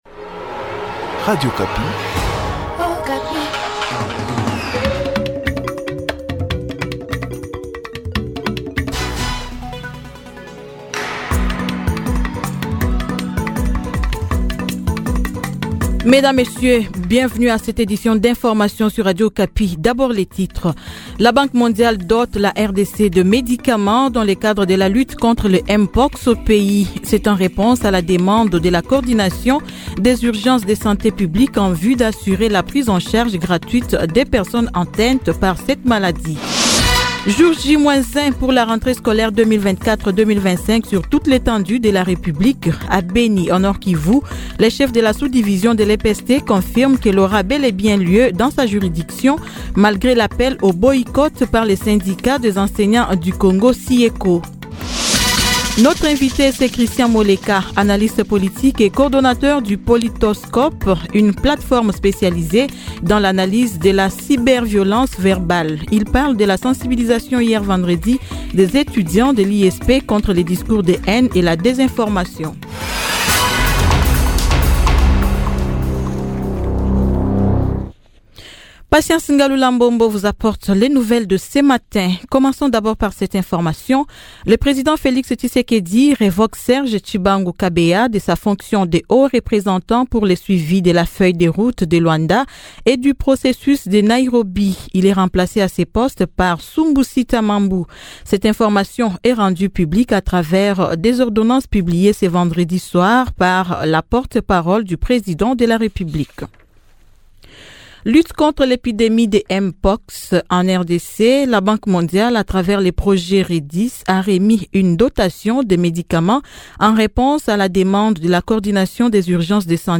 Journal matin 06H-07H